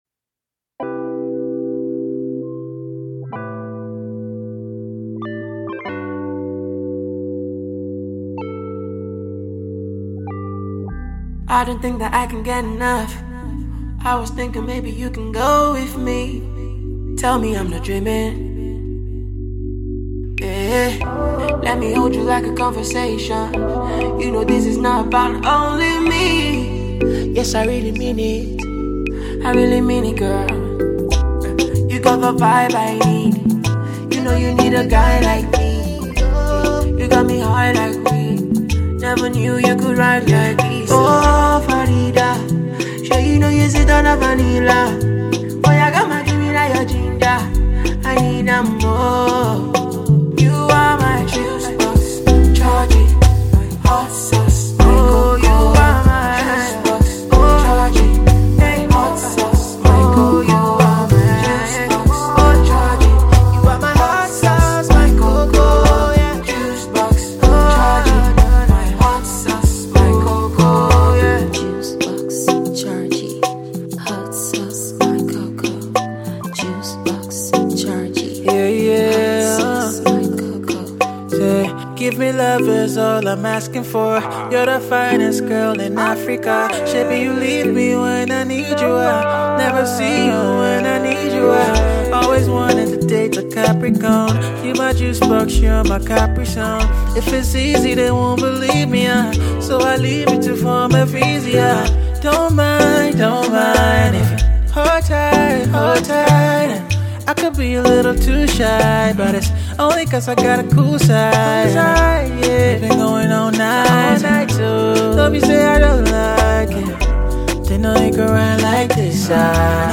Injected with smooth singing too